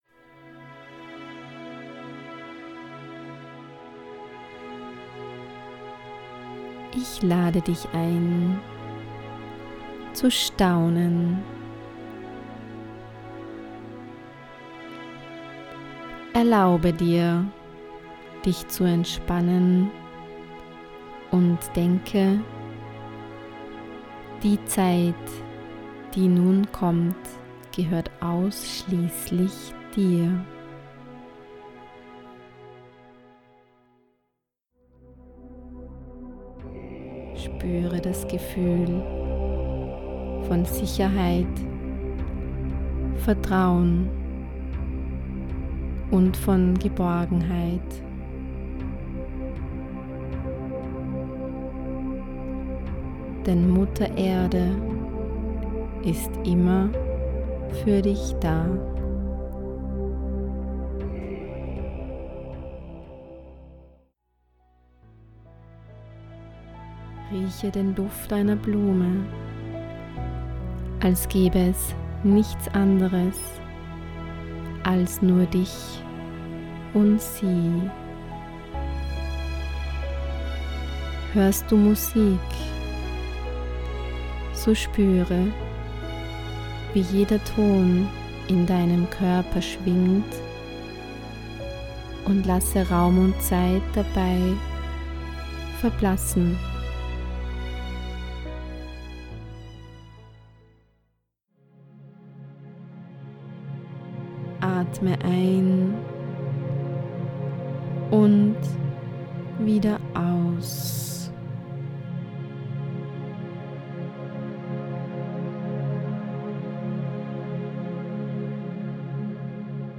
7 geführte Meditationen für mehr Leichtigkeit im Leben
• Erdige Ohm-Chöre unterstützen Sie dabei, sich zu erden und zu stärken.
• Untermalt von stimmungsvoller und entspannender Alphawellen-Musik
Tolle Tonqualität, Texte mit Tiefgang, beruhigende Stimme.